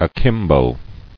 [a·kim·bo]